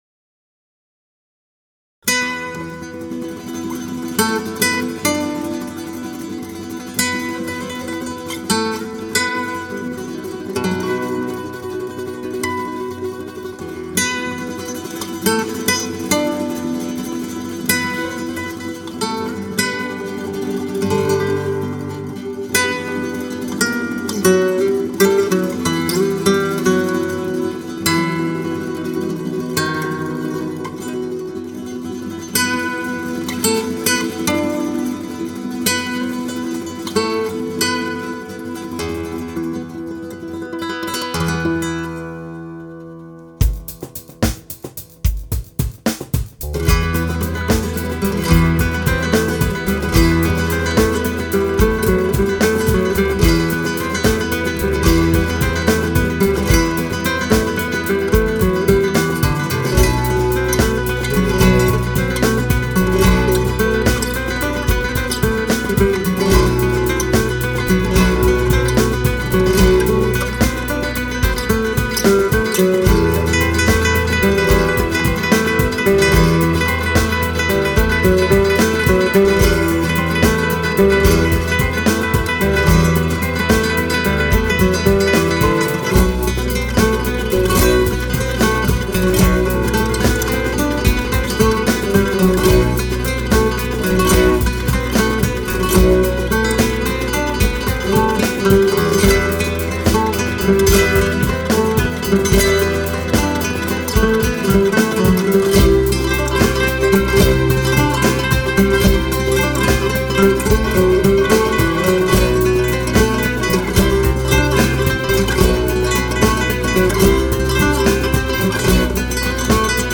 Instrumental/Flamenco/New Age